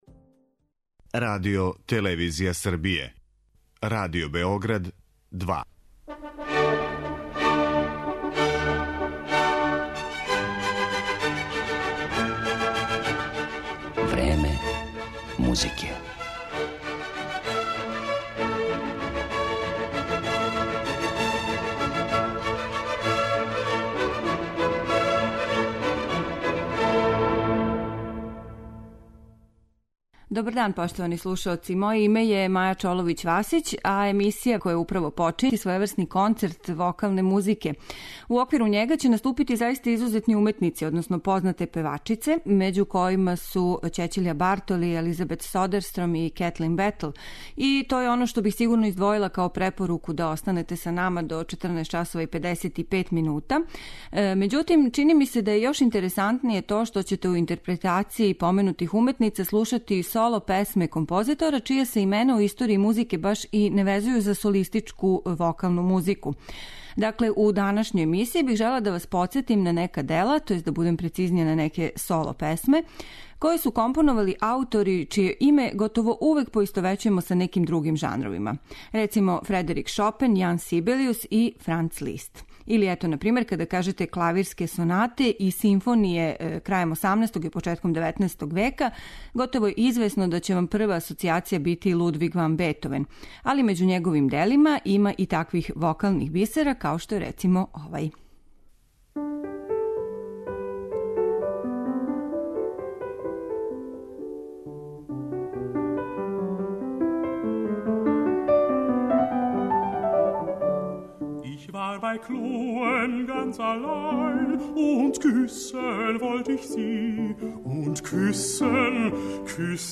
Слушаћете соло песме композитора чија имена поистовећујемо са другим музичким жанровима.
Изабране песме ћете слушати у интерпретацији Ћећилије Бартоли, Елизабет Содерстром, Кетлин Бетл и Соиле Исокоски.